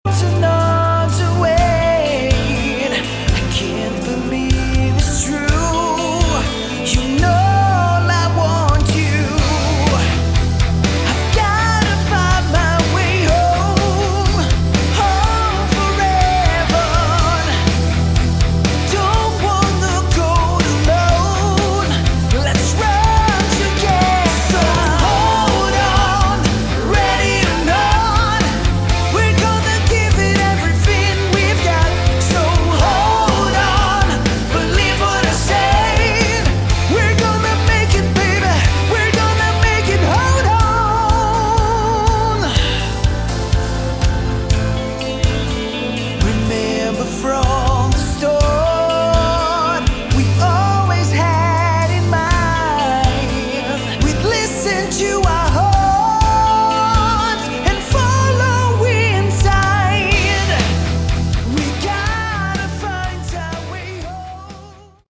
Category: Melodic Rock
vocals
guitars
bass, keyboards, backing vocals
drums